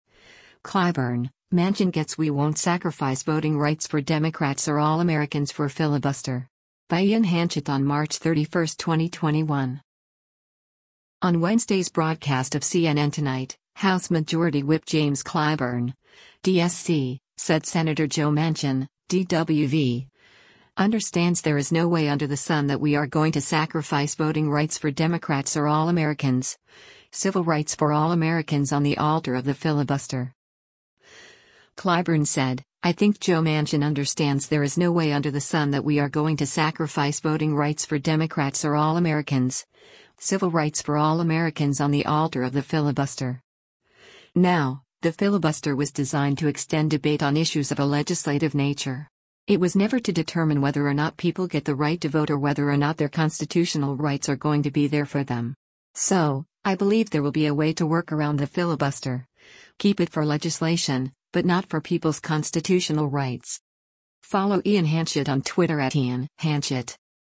On Wednesday’s broadcast of “CNN Tonight,” House Majority Whip James Clyburn (D-SC) said Sen. Joe Manchin (D-WV) “understands there is no way under the sun that we are going to sacrifice voting rights for Democrats or all Americans, civil rights for all Americans on the altar of the filibuster.”